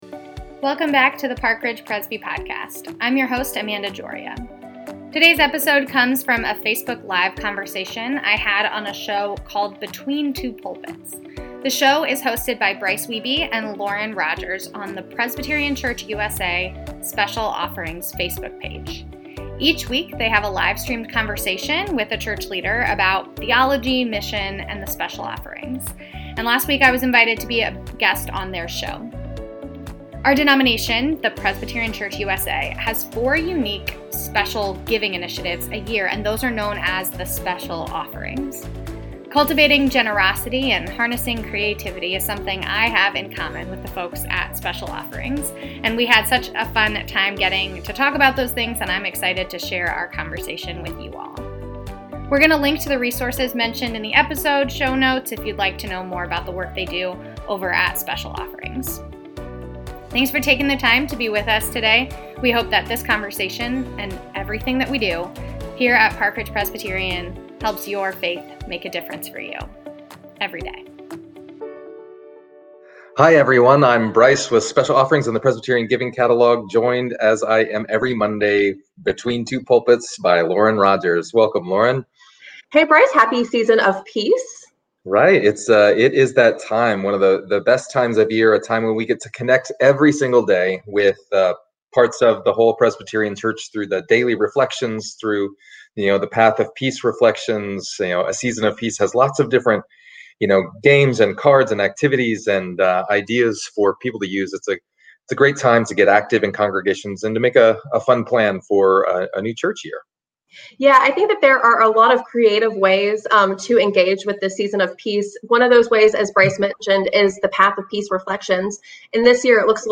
Between Two Pulpits, a weekly live-streamed conversation with church leaders about theology, mission and the Special Offerings